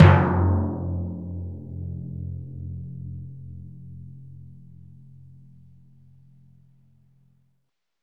Index of /90_sSampleCDs/Roland LCDP03 Orchestral Perc/CMB_Orch Combo 1/CMB_Orch Kit 2
PRC TIMP F1H.wav